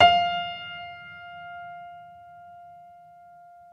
Vintage_Upright